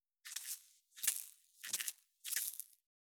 381,ふりかけ,サラサラ,パラパラ,ジャラジャラ,
効果音厨房/台所/レストラン/kitchen
効果音